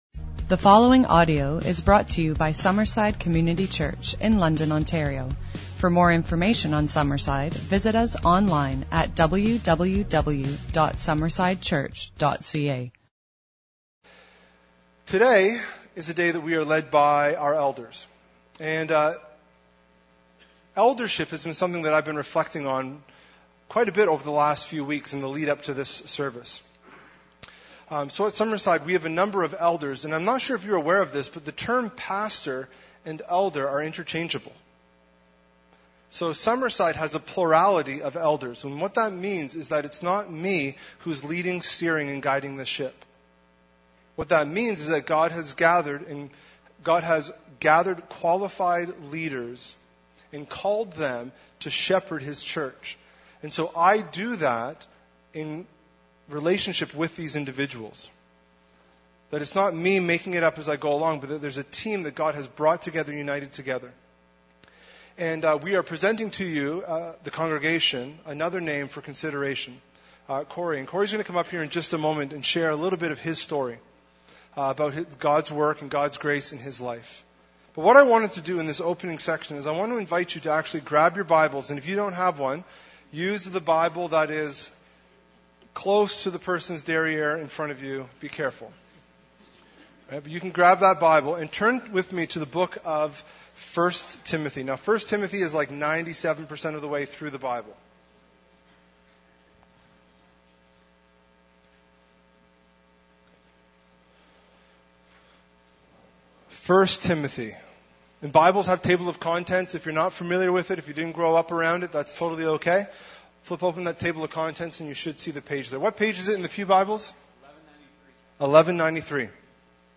This was a special service led by some of our elders.